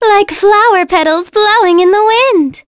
Cassandra-Vox_Skill7.wav